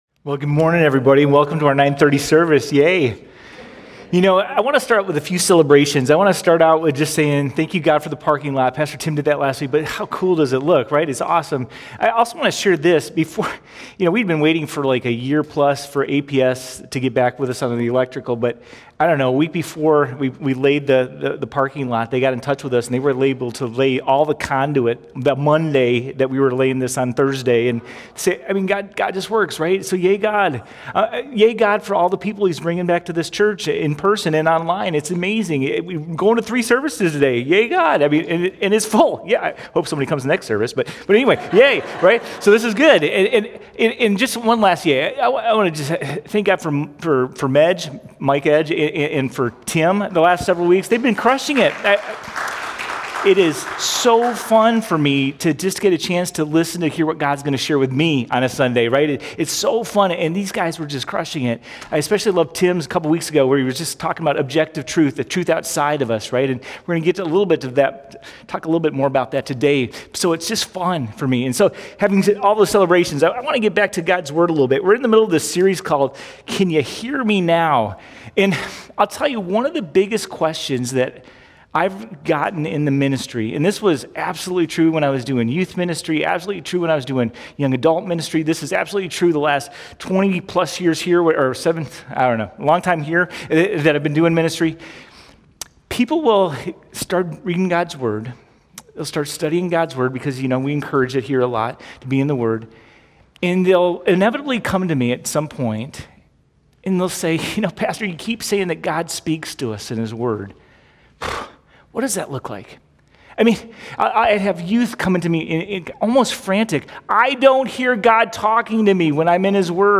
87Sermon.mp3